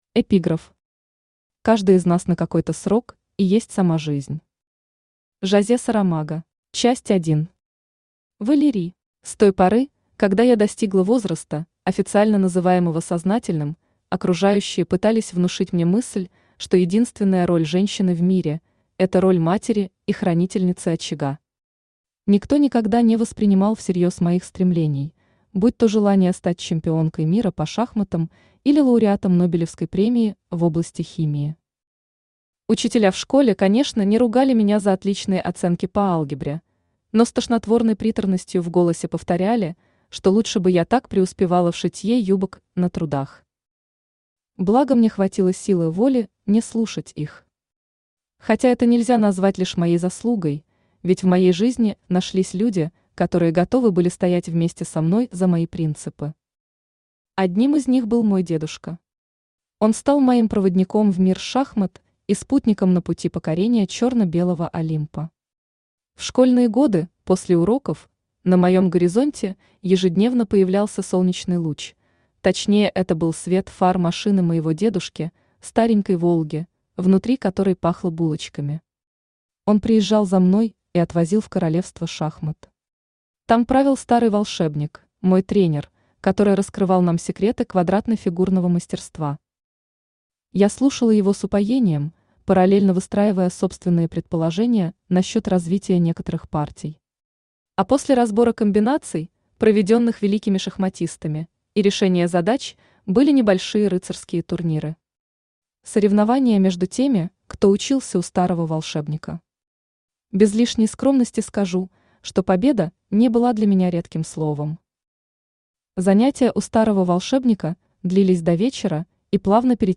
Аудиокнига Рассветы наших дней | Библиотека аудиокниг
Aудиокнига Рассветы наших дней Автор Влада Арт Читает аудиокнигу Авточтец ЛитРес.